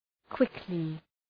Προφορά
{‘kwıklı}
quickly.mp3